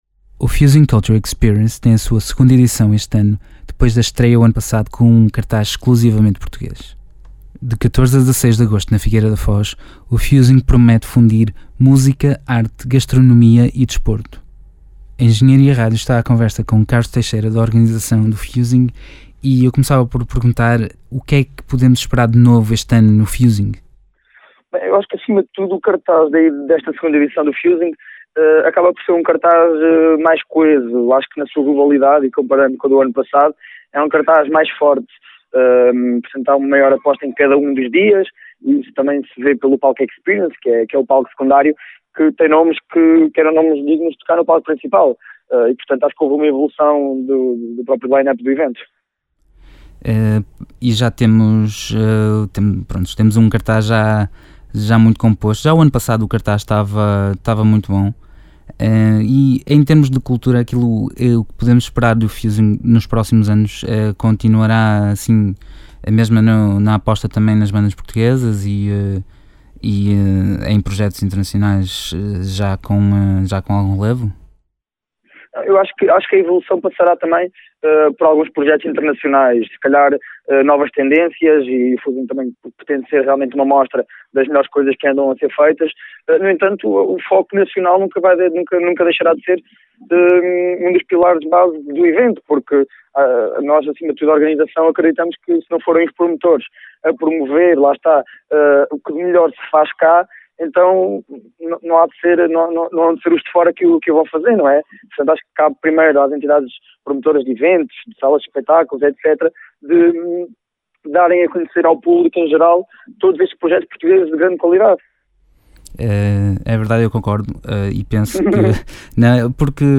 Entrevista – Fusing Culture Experience 2014 - Engenharia Rádio
Entrevista_Fusing.mp3